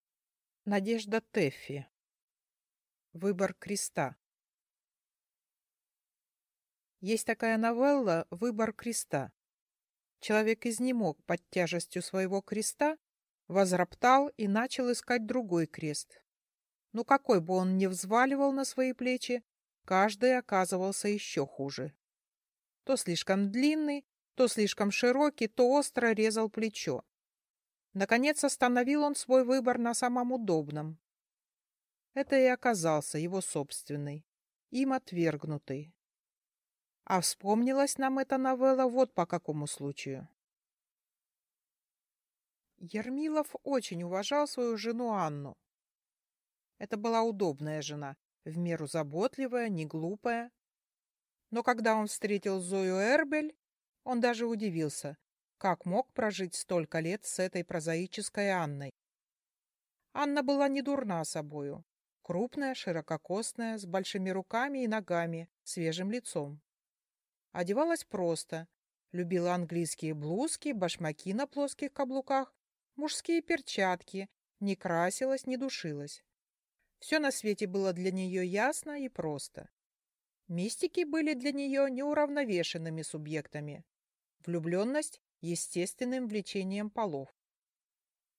Aудиокнига Выбор креста